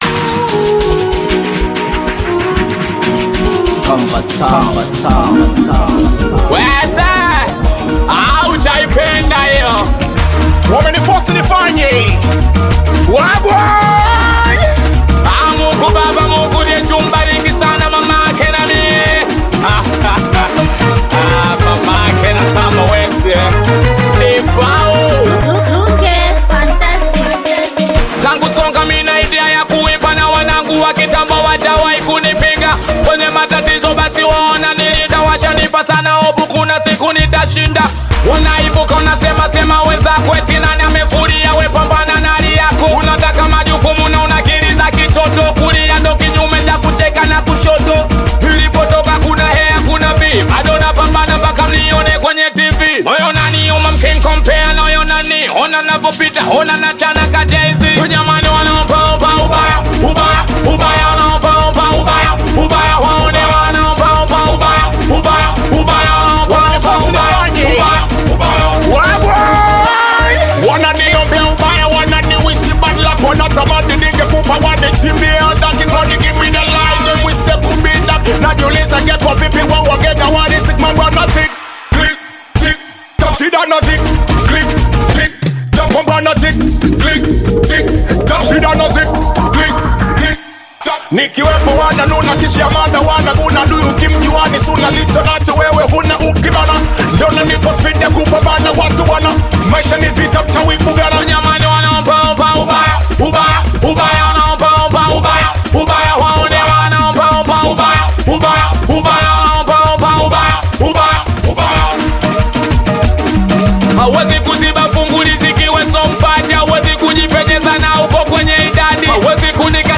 Singeli music track
Tanzanian Bongo Flava Singeli artists